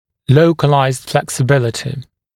[‘ləukəlaɪzd ˌfleksɪ’bɪlətɪ][‘лоукэлайзд ˌфлэкси’билэти]гибкость в конкретном месте